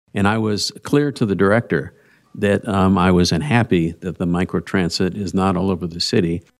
Mayor Pro Tempore Jim Pearson, who sits on the Metro Transit board, says he agrees, but says this is just a pilot and that is still a possibility.